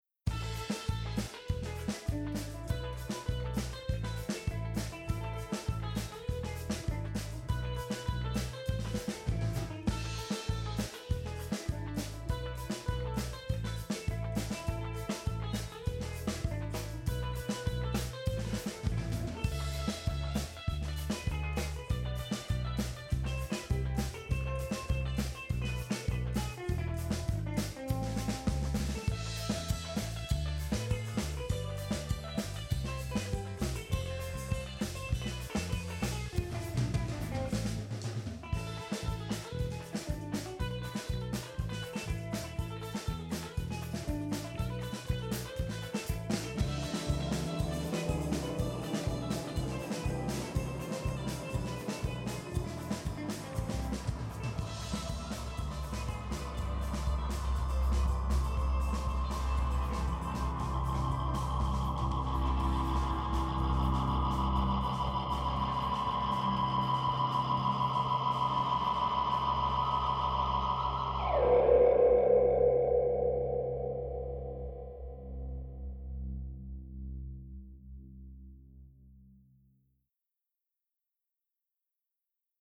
Pop Instrumental
guitarra